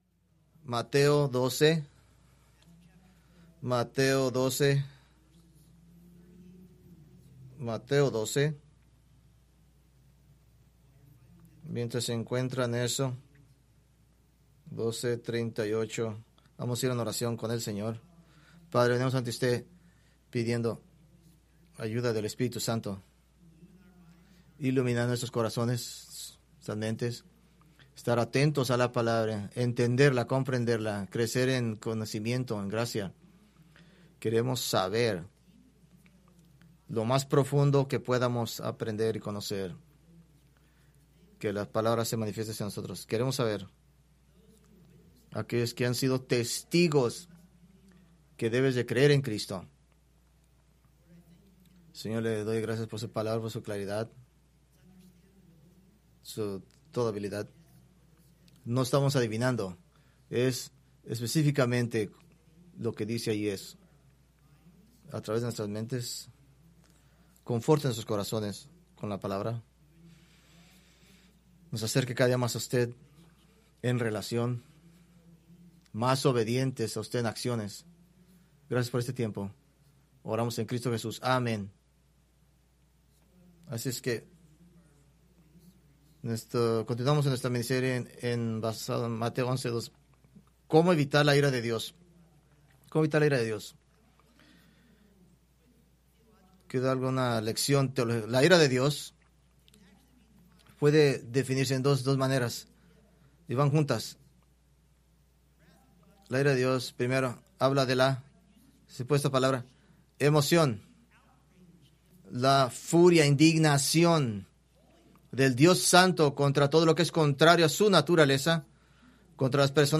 Preached January 18, 2026 from Mateo 12:38-42